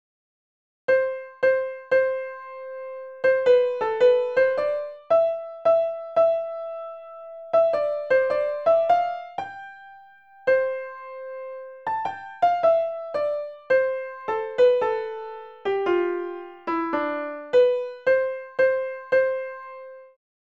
Back in the hamburger menu, you can change the instrument from a classic grand piano, to a xylophone or to a toy piano!